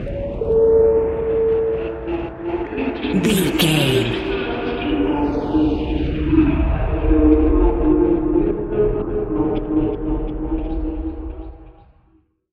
Electronic loops, drums loops, synth loops.,
Epic / Action
Fast paced
Ionian/Major
Fast
aggressive
industrial
energetic
hypnotic